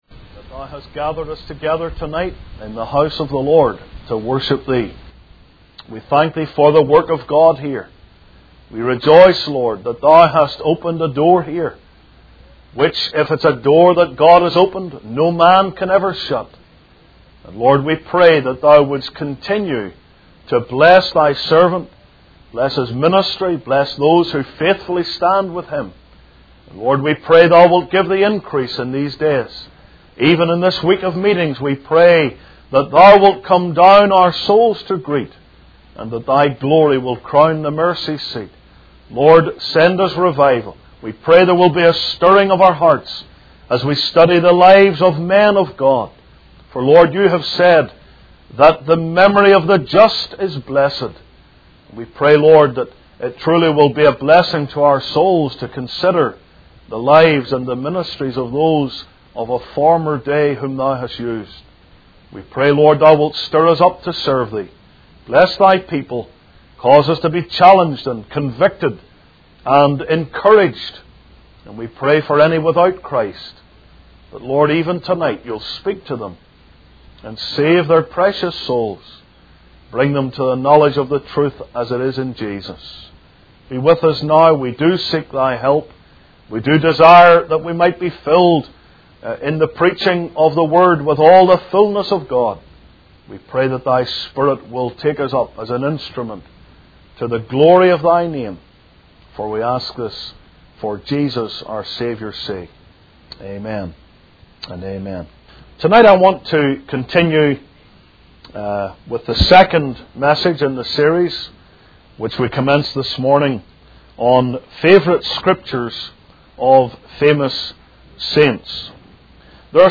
In this sermon, the speaker tells the story of a young man in the early summer of 1744 who had a deep desire to bring the gospel to the Native American tribes near the Delaware River.